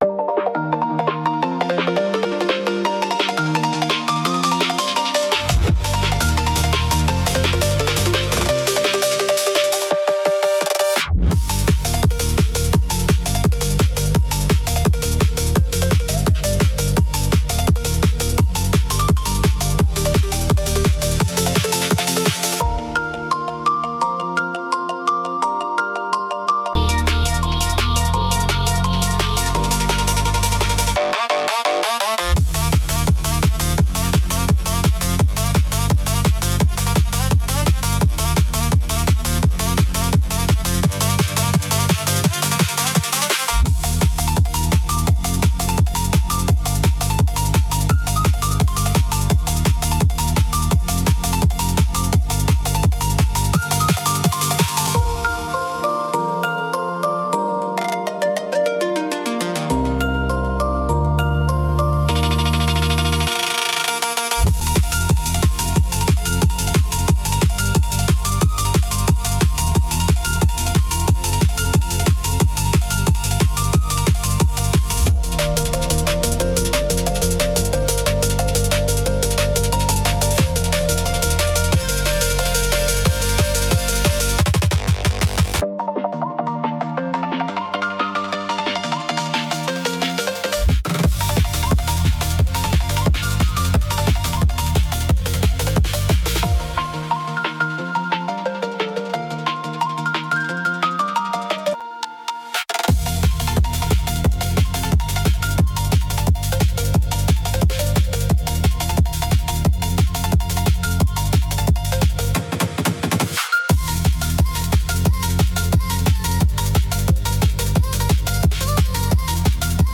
There are no lyrics because it is an instrumental.